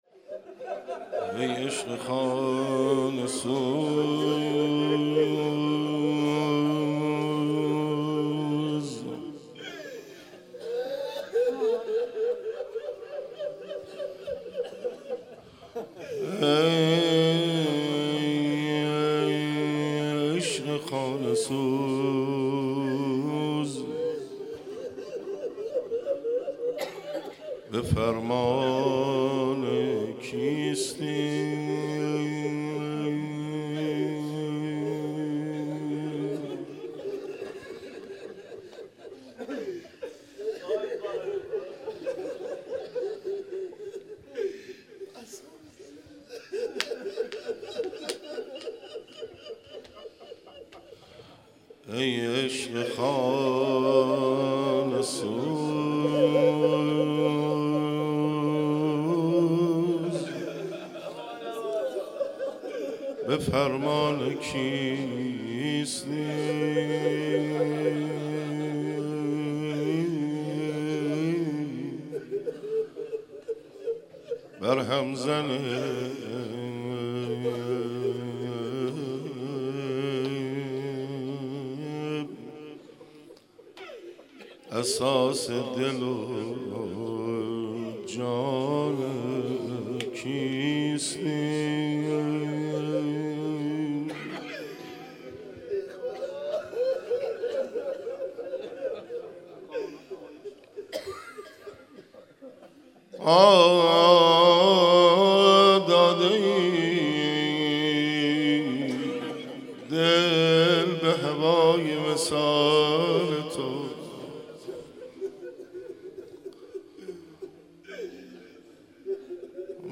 مناسبت : دهه اول صفر
قالب : روضه